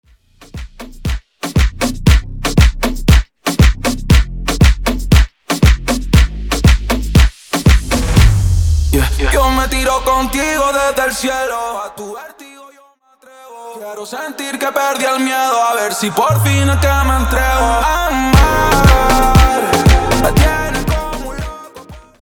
Intro Acapella Dirty